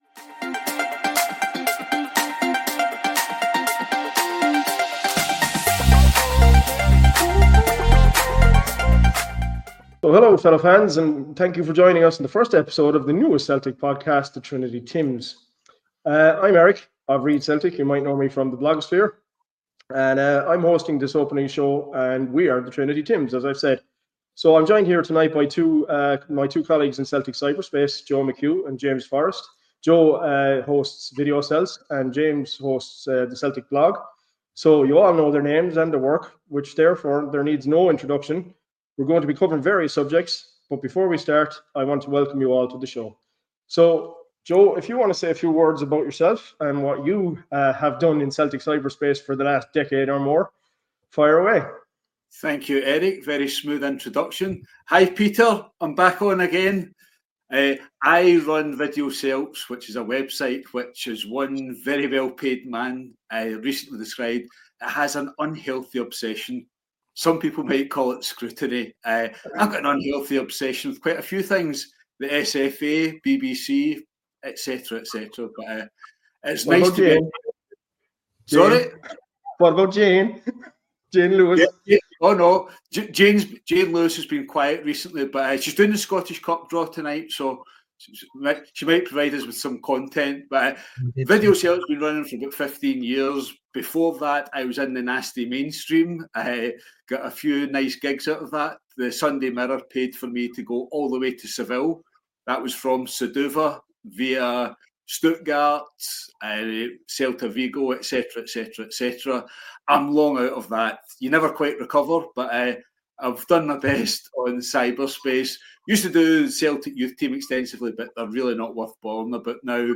Headliner Embed Embed code See more options Share Facebook X Subscribe In this, the first Trinity Tims Podcast, The Opening Goal, our three bloggers discuss Brugge, Ross County, our predictions for Aberdeen, the AGM ... and Ibrox's public park style walking pace football.